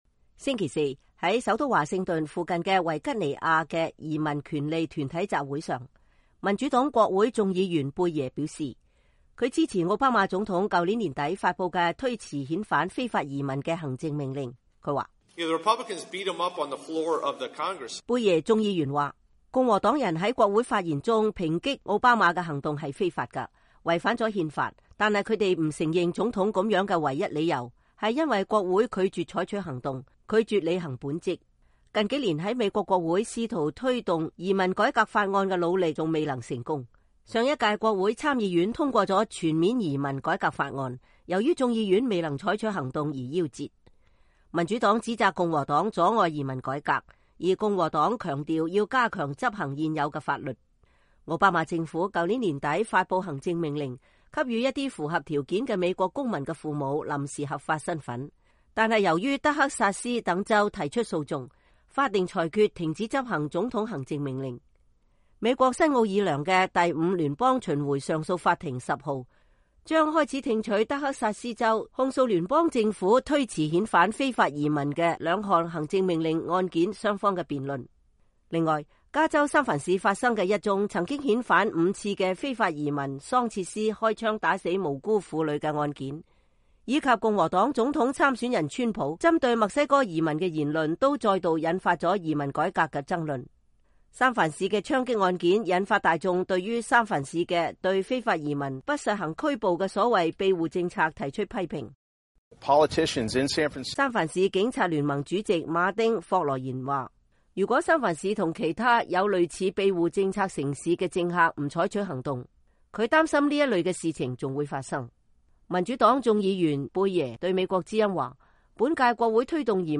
民主黨國會眾議員貝耶在首都華盛頓附近的維吉尼亞的移民權利團體集會上發言 （VOA視頻截圖）
7月9日（星期四），在首都華盛頓附近的維吉尼亞的移民權利團體集會上，民主黨國會眾議員貝耶(Rep. Don Beyer)說，他支持奧巴馬總統去年底發布的推遲遣返非法移民的行政命令。